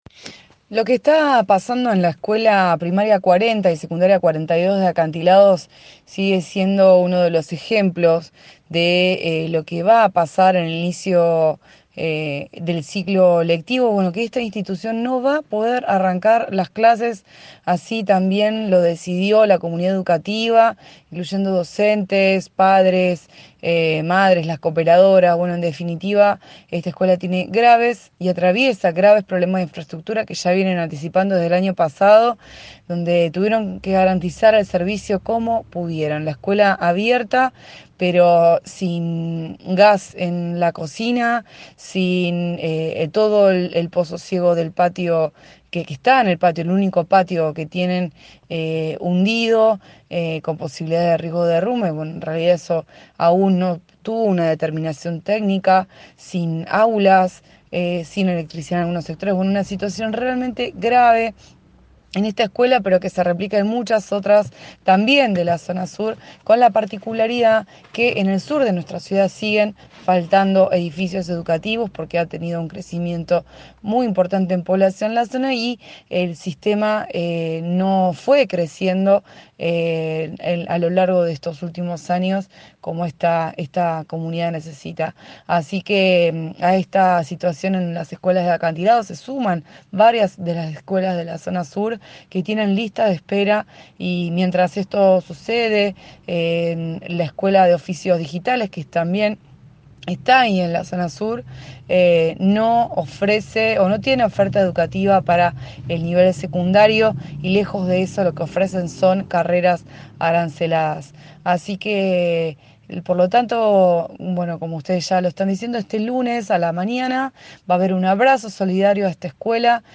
Eva Fernández, consejera escolar de Unidad Ciudadana, comentó en el programa radial Bien Despiertos, emitido de lunes a viernes de 7:00 a 9:00 por De la Azotea 88.7: “La escuela no tiene gas en la cocina, el pozo ciego del patio está hundido con posibilidad de derrumbe (aunque todavía no hay un informe técnico.) Faltan aulas y electricidad en algunas zonas.”